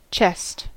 Ääntäminen
IPA : /t͡ʃɛst/